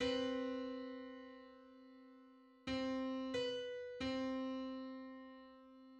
Seventeenth subharmonic 32 17 = 1.88235...
play 1095.04 −4.96
17th_subharmonic_on_C.mid.mp3